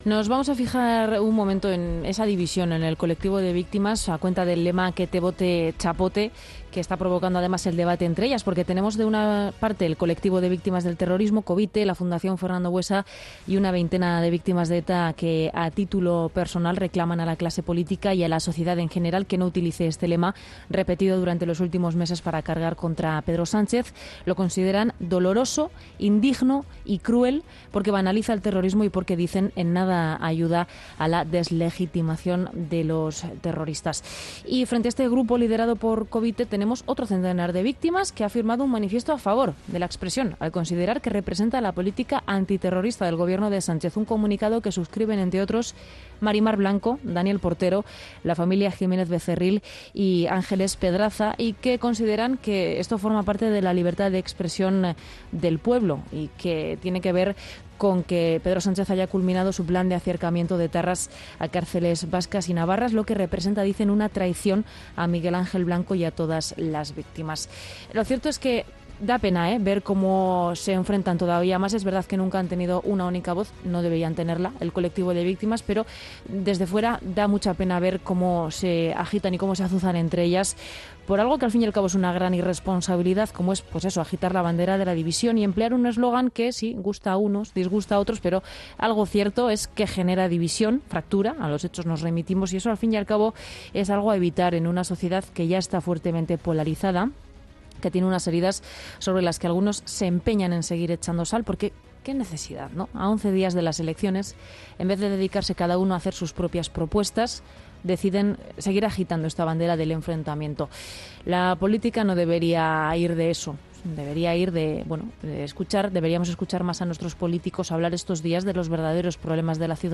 El editorial